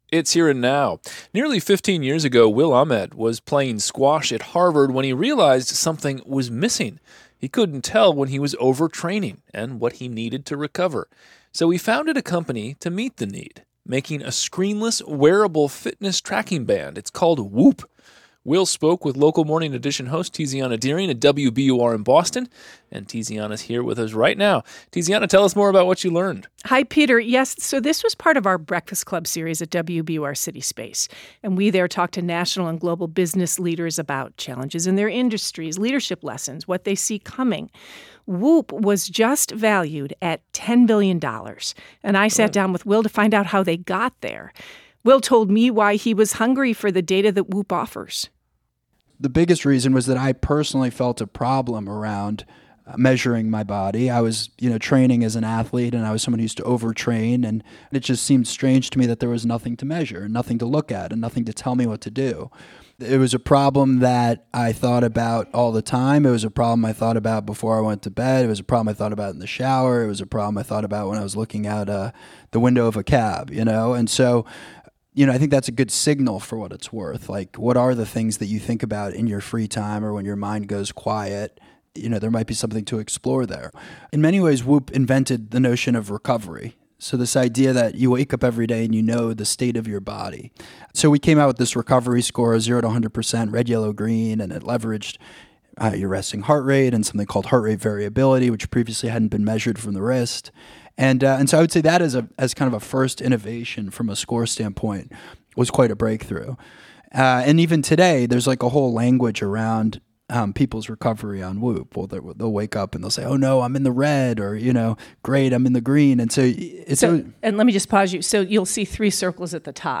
as part of The WBUR Breakfast Club . They discussed the inspiration for WHOOP and how it grew from an experiment in the Harvard Innovation Lab into a $10 billion company.